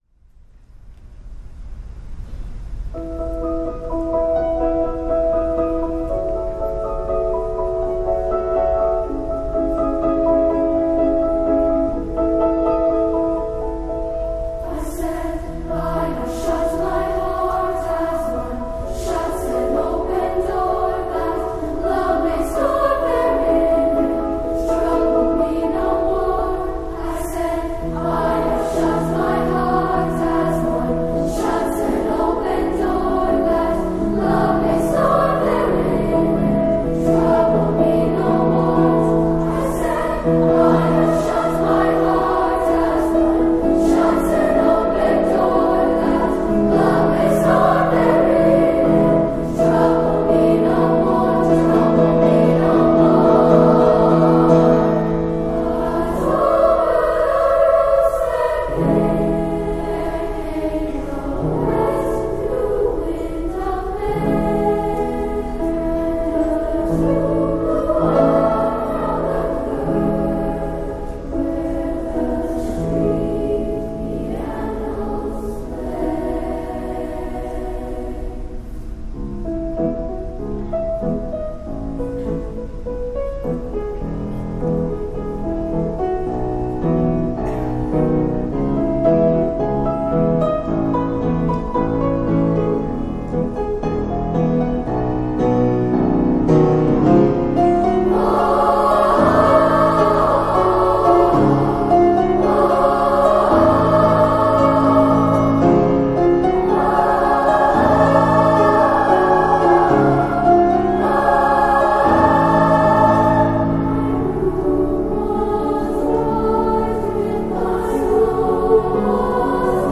Voicing: SSAA,Pno